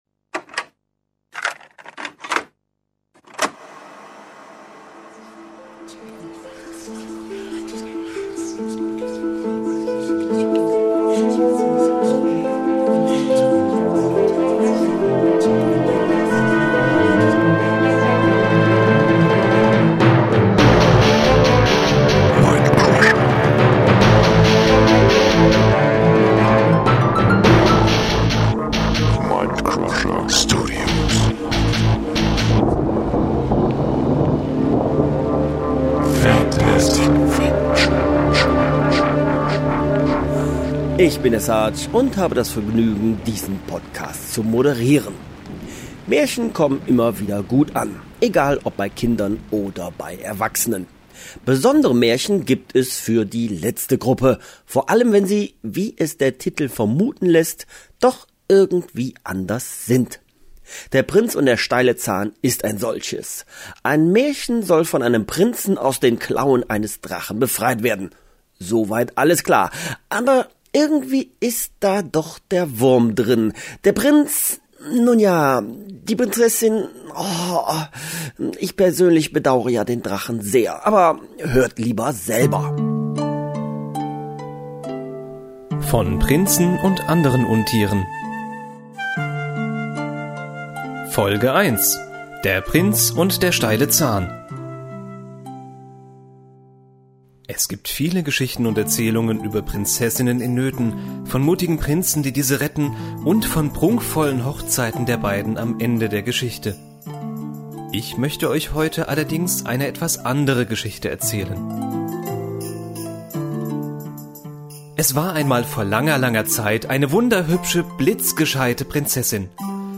Der-Prinz-und-der-steile-Zahn-Interview.mp3